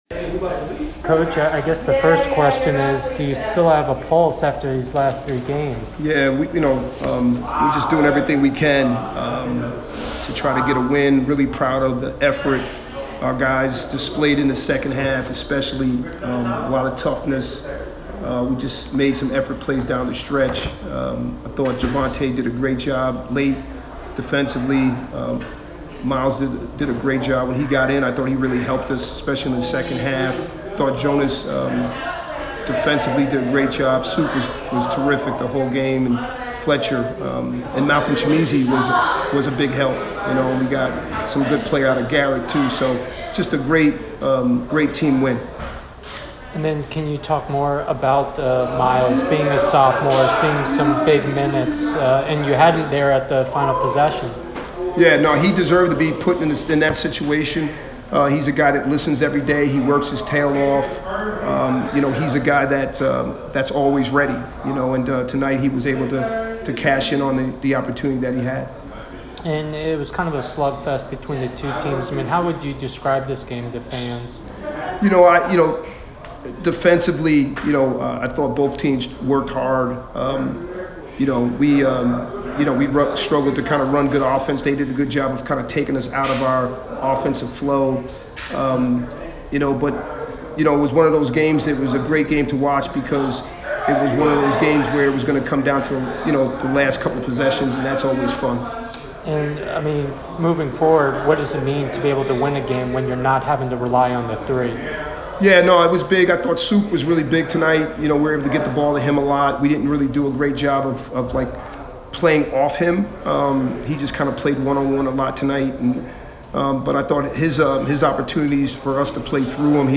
GW MBB Postgame Interview